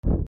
日常・種火に火が付く（ボッ） 01